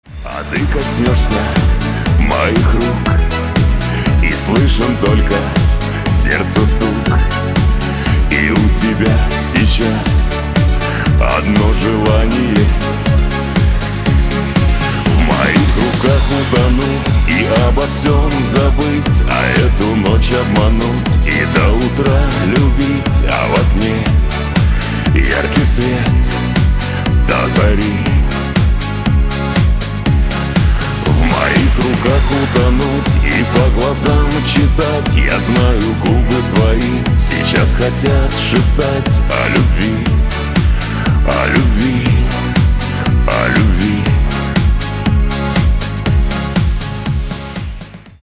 • Качество: 320, Stereo
мужской вокал
русский шансон